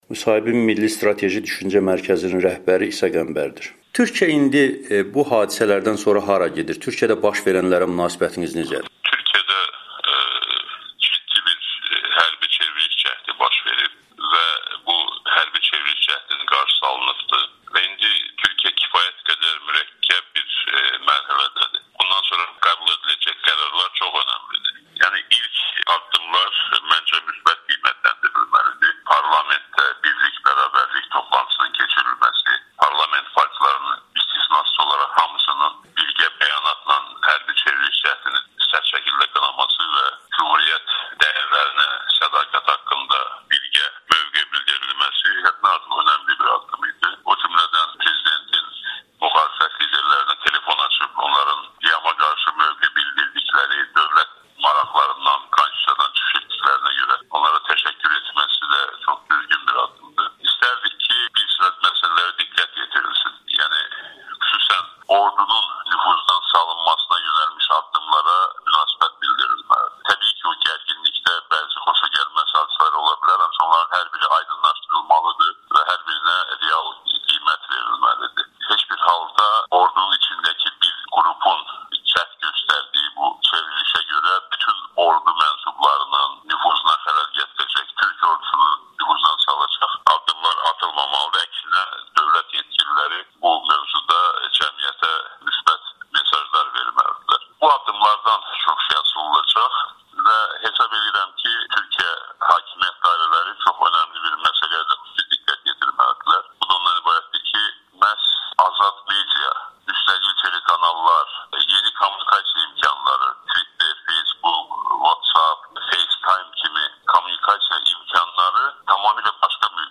Milli Strateji Düşüncə Mərkəzinin rəhbəri İsa Qəmbərin Amerikanın Səsinə müsahibəsi